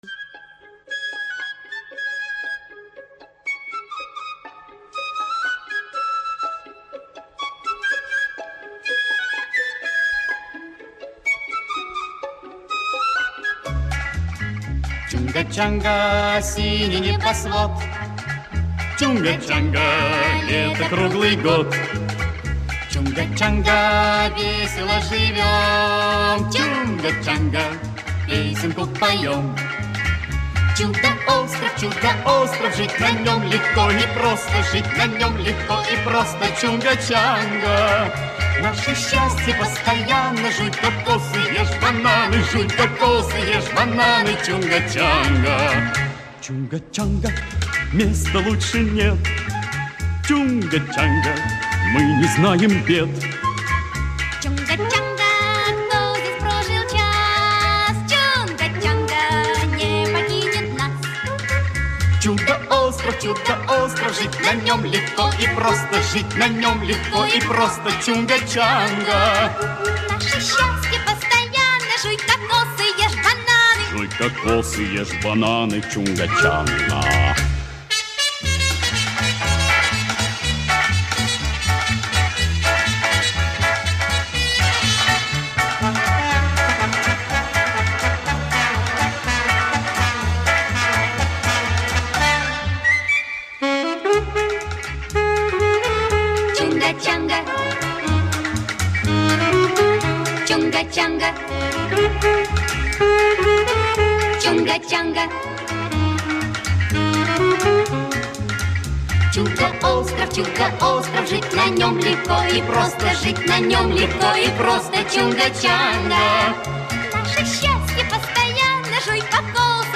Жанр: Лучшие детские песенки Слушали